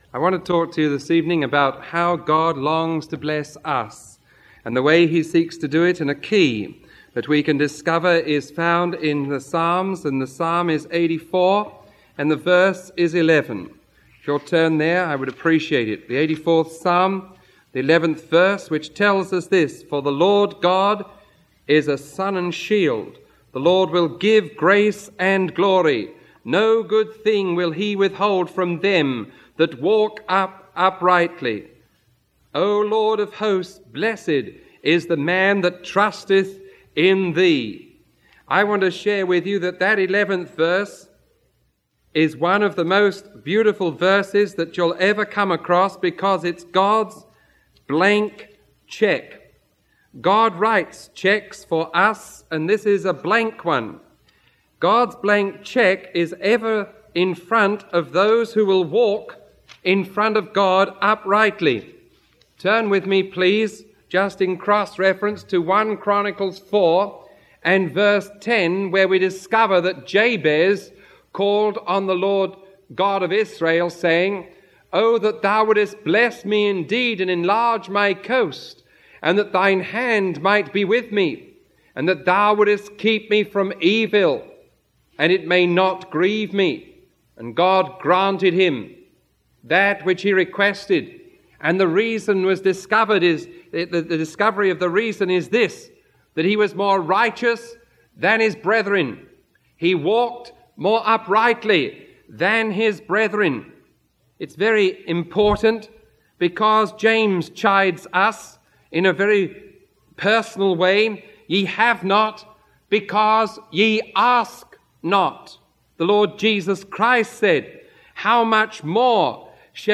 Sermon 0036AB recorded on April 1